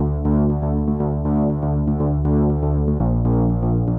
The Tech (Bass) 120BPM.wav